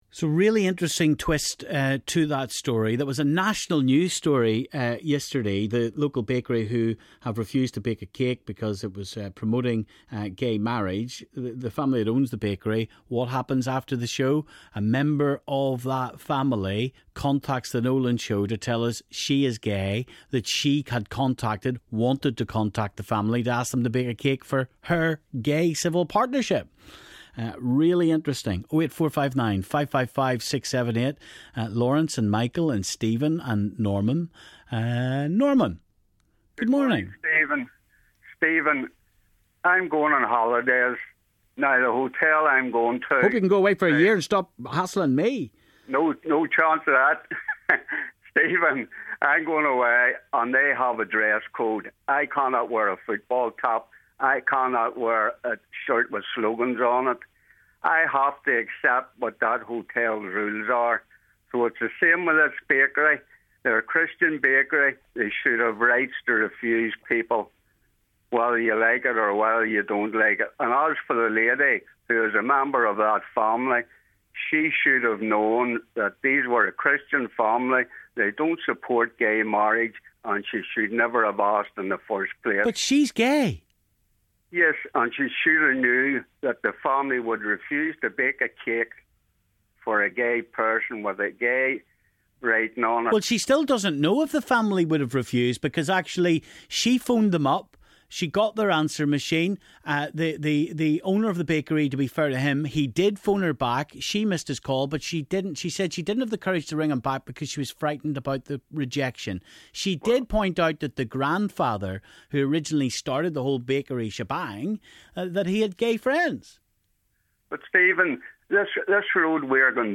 Caller response to the Bert and Ernie cake row.